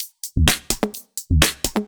Index of /VEE/VEE2 Loops 128BPM
VEE2 Electro Loop 162.wav